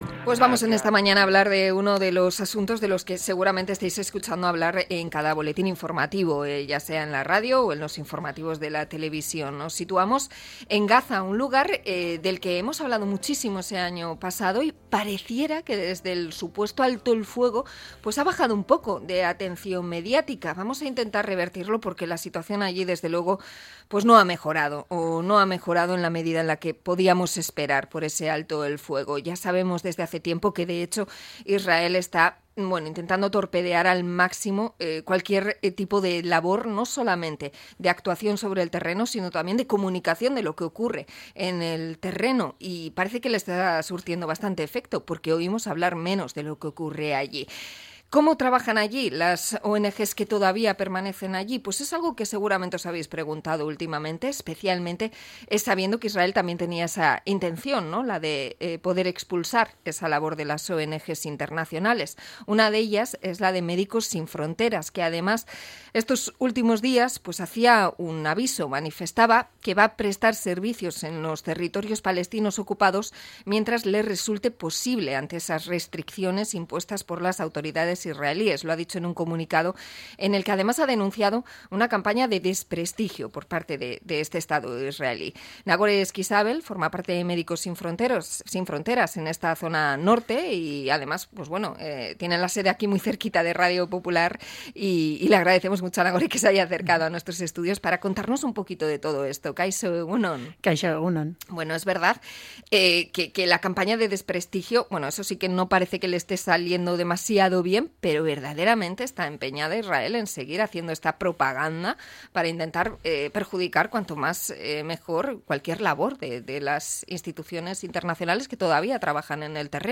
Entrevista a Médicos sin fronteras por la situación en Gaza